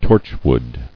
[torch·wood]